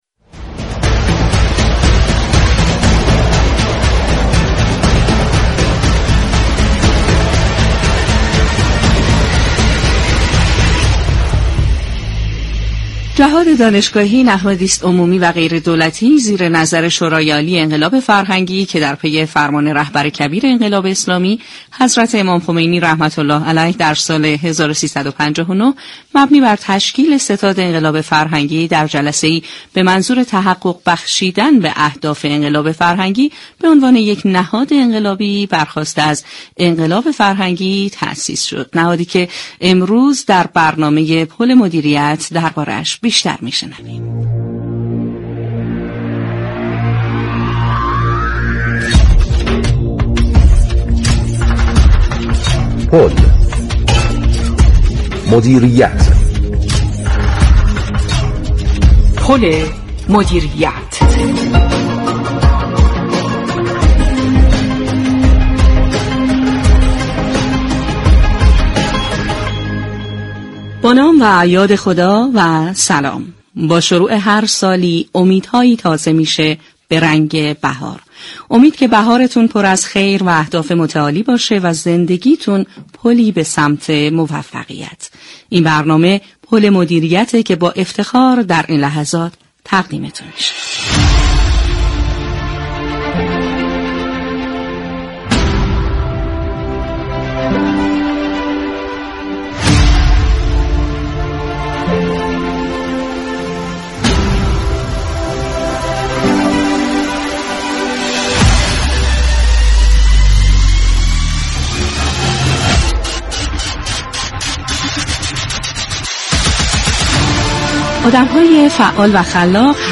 به گزارش پایگاه اطلاع رسانی رادیو تهران، دكتر حسن مسلمی نائینی رئیس جهاد دانشگاهی در گفتگو با برنامه «پل مدیریت» رادیو تهران با بیان اینكه جهاد دانشگاهی با نظر حكیمانه امام خمینی(ره) پس از پیروزی انقلاب اسلامی تحت ستاد انقلاب و بعد از آن آن شورای انقلاب فرهنگی با هدف كمك در حوزه‌های پژوهشی و مدیریتی و آموزشی و فرهنگی و كمك به نهادهای علمی كشور تاسیس شد گفت: استفاده از توان جوانان كشور با باورهای دینی و ایمان و علم، منشأ خیرات فراوانی از ابتدای انقلاب، دوران دفاع مقدس و تا كنون بوده است.